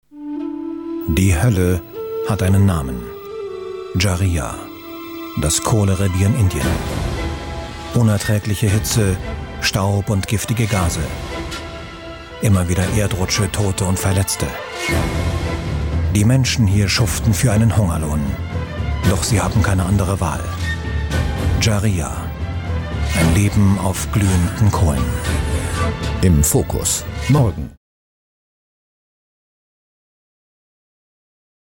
Profi-Sprecher deutsch.
Kein Dialekt
Sprechprobe: Industrie (Muttersprache):
german voice over artist